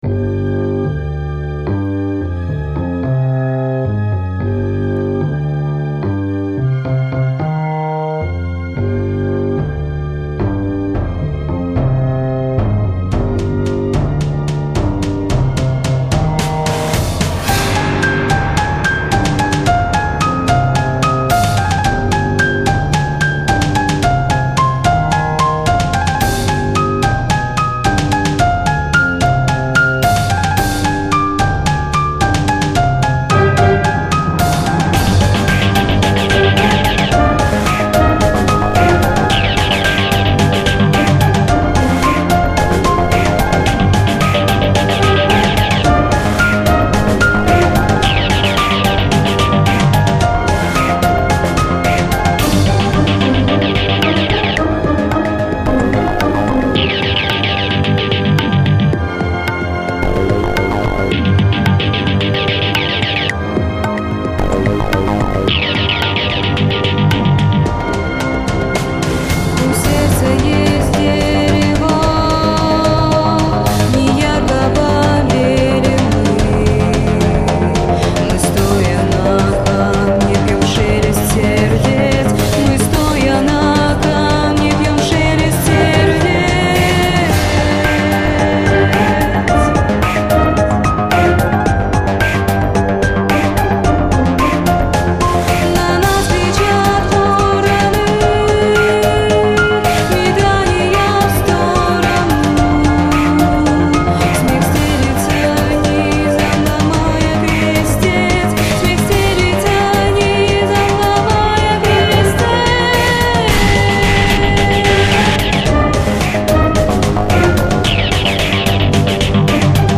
darkwave